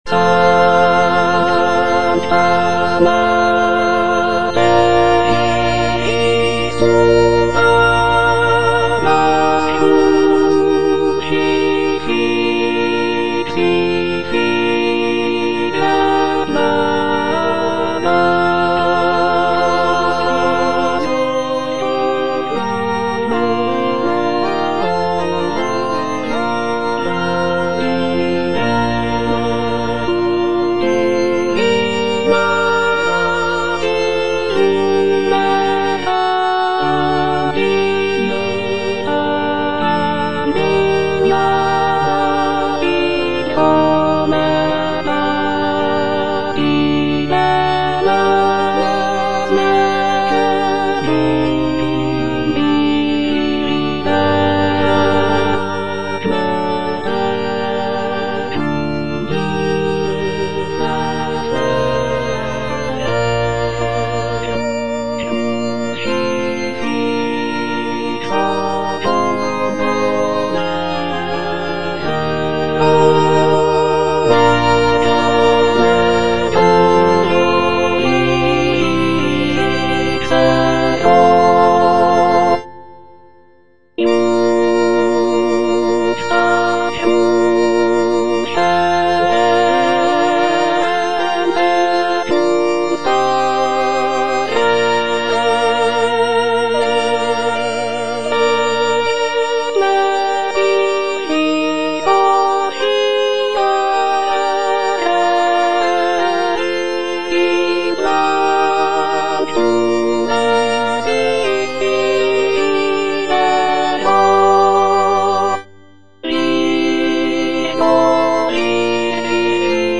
G.P. DA PALESTRINA - STABAT MATER Sancta Mater, istud agas (alto I) (Emphasised voice and other voices) Ads stop: auto-stop
sacred choral work
Composed in the late 16th century, Palestrina's setting of the Stabat Mater is known for its emotional depth, intricate polyphonic textures, and expressive harmonies.